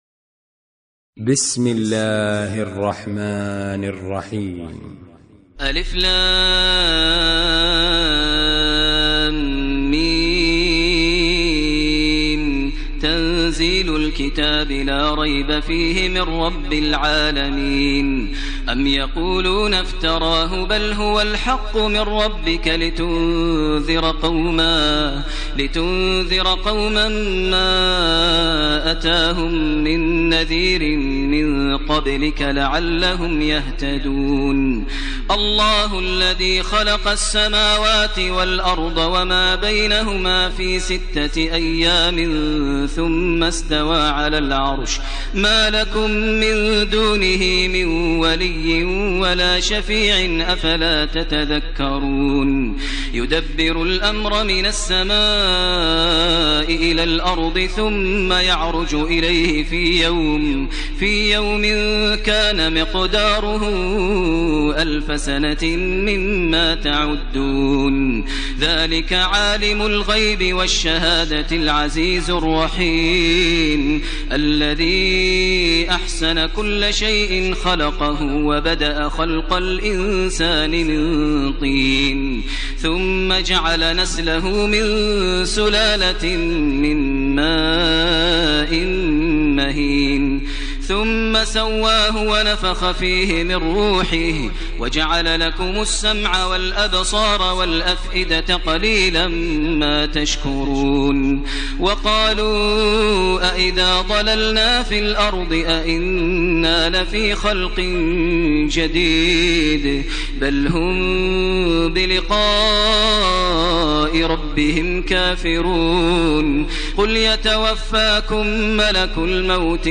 ترتیل سوره سجده با صدای ماهر المعیقلی
032-Maher-Al-Muaiqly-Surah-As-Sajda.mp3